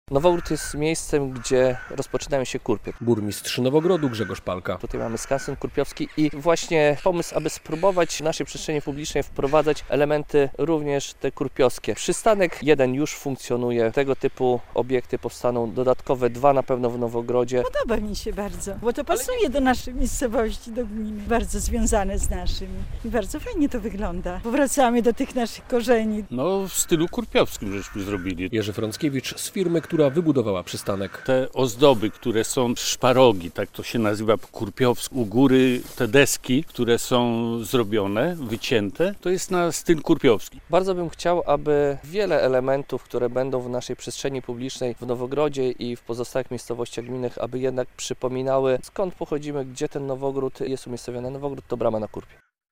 Bardzo bym chciał, żeby było więcej elementów, które przypominają o naszej tradycji - mówi burmistrz Nowogrodu Grzegorz Palka.